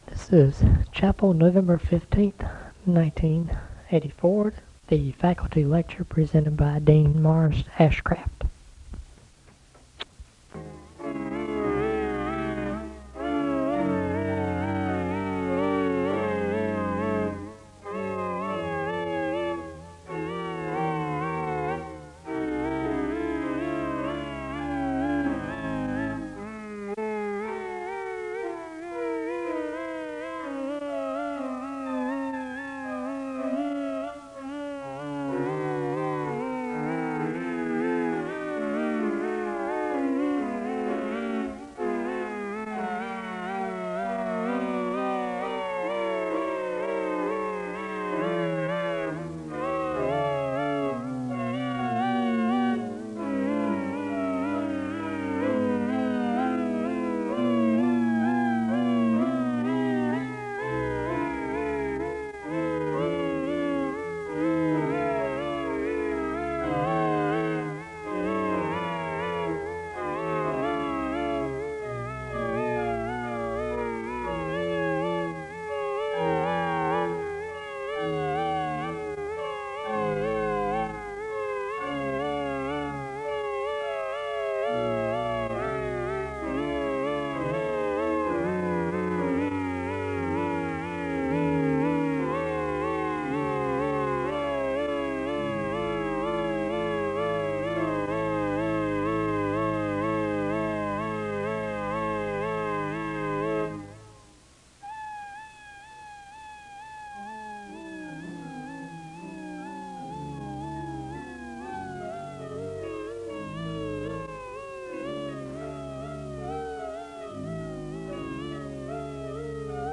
The service begins with organ music (00:00-06:24).
Location Wake Forest (N.C.)